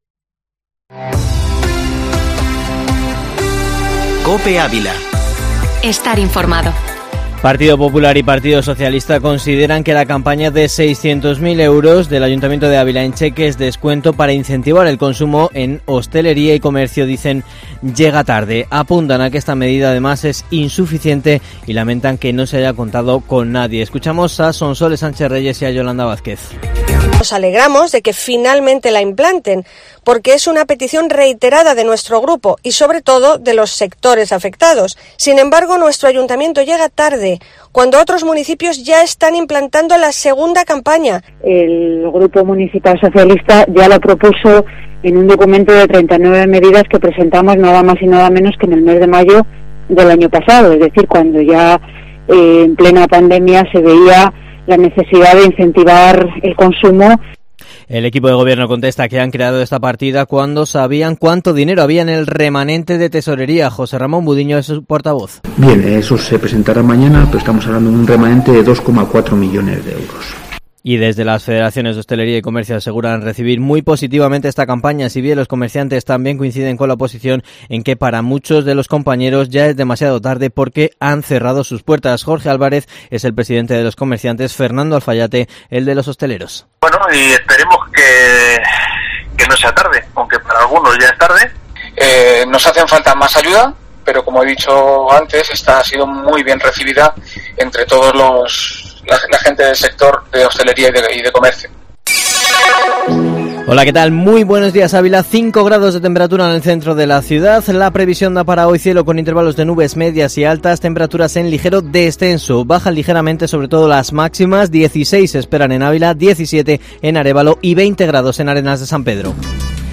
Informativo matinal Herrera en COPE Ávila 26/03/2021